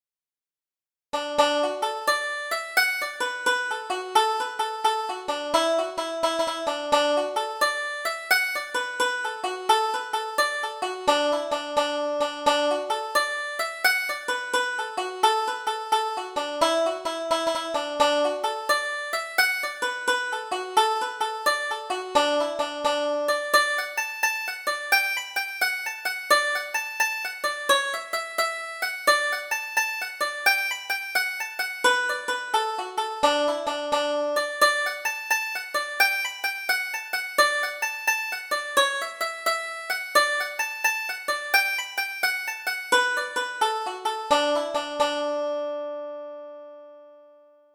Double Jig: Jerry's Beaver Hat